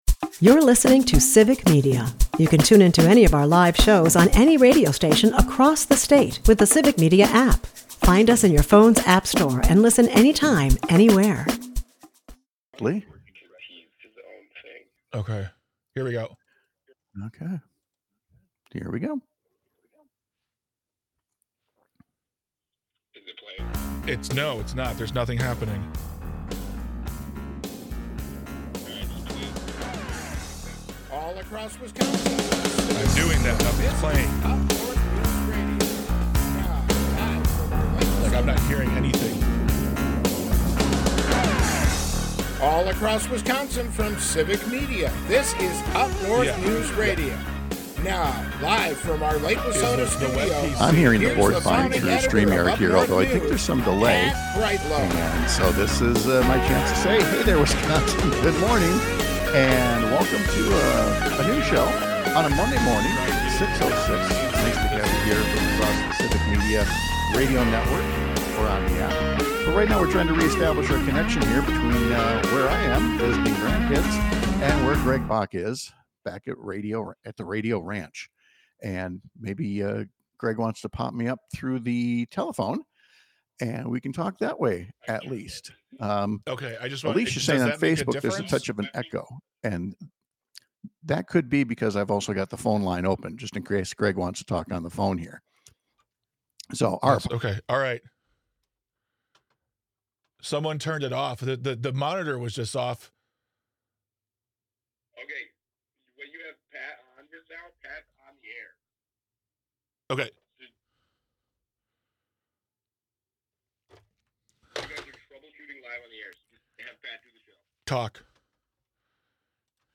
airs on several stations across the Civic Media radio network, Monday through Friday from 6-8 am